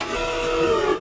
SERVO SE11.wav